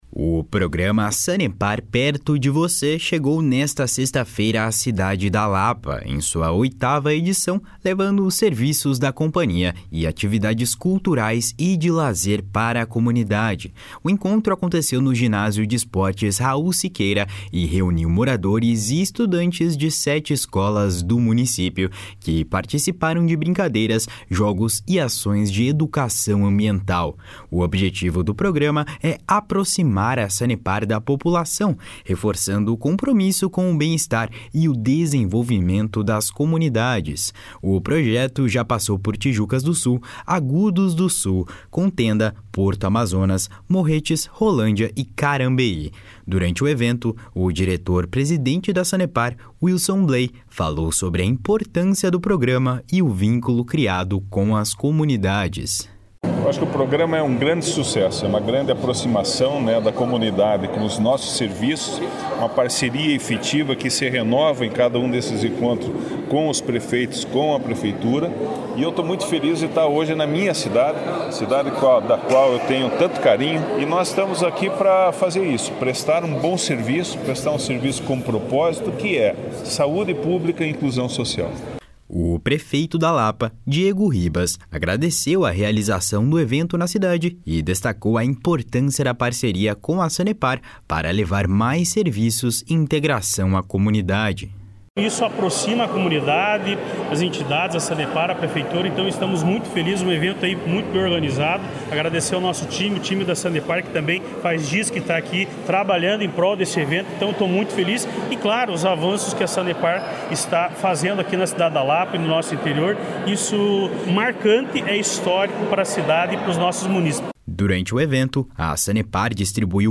O prefeito da Lapa, Diego Ribas, agradeceu a realização do evento na cidade e destacou a importância da parceria com a Sanepar para levar mais serviços e integração à comunidade. // SONORA DIEGO RIBAS //